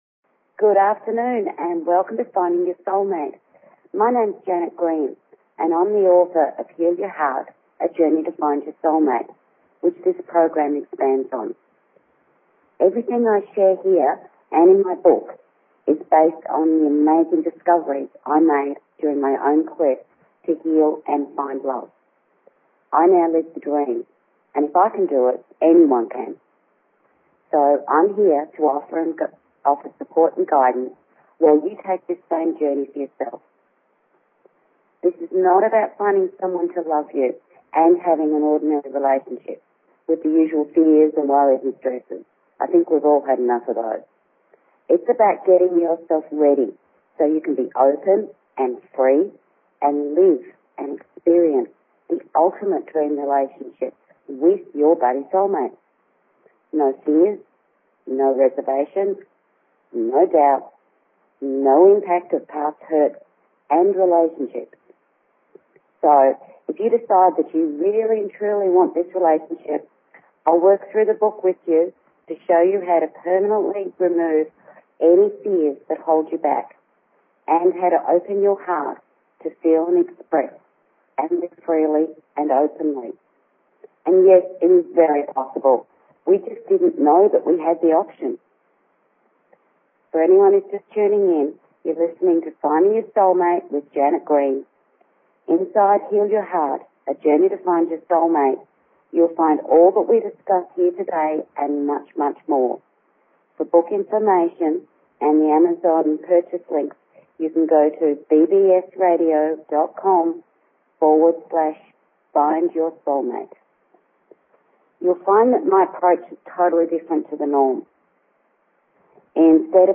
Talk Show Episode, Audio Podcast, Find_Your_SOUL_MATE and Courtesy of BBS Radio on , show guests , about , categorized as